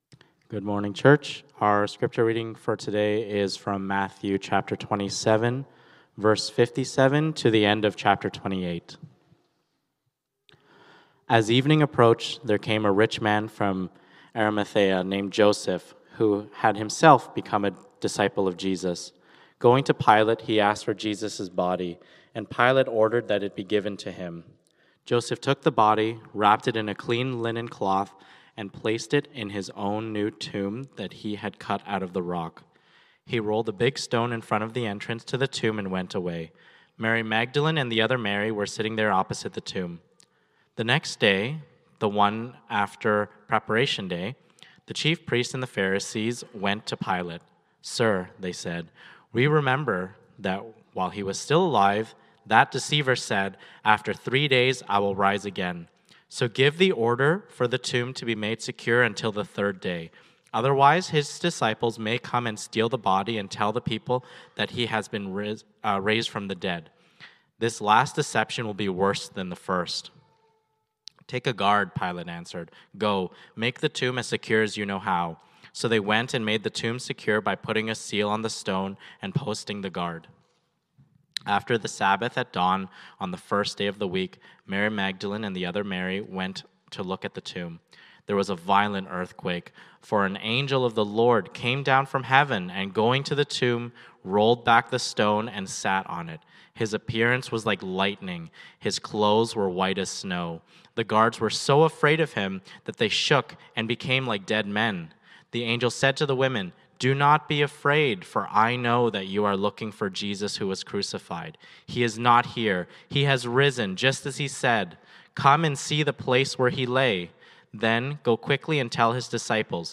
Sermons - Forward Baptist Church, Toronto